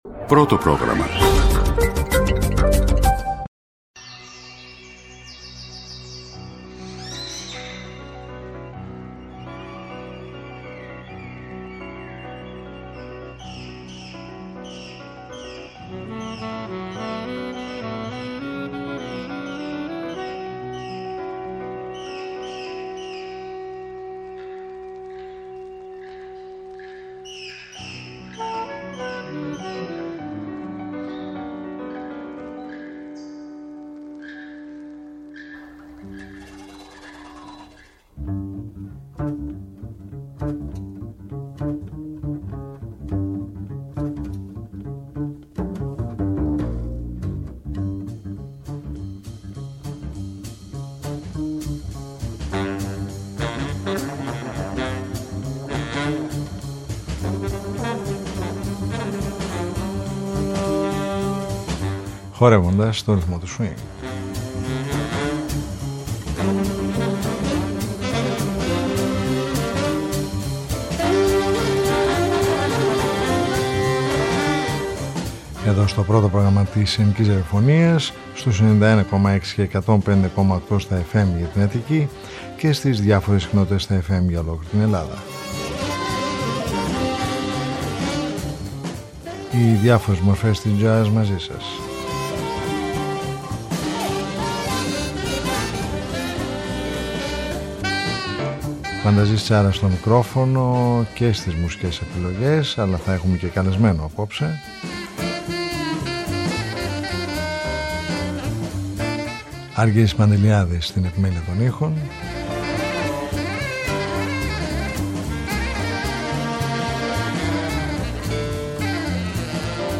Χορεύοντας στον ρυθμό του Swing : Μια εβδομαδιαία ωριαία συνάντηση με τις διάφορες μορφές της διεθνούς και της ελληνικής jazz σκηνής, κάθε Σάββατο στις 23:00 στο πρώτο Πρόγραμμα.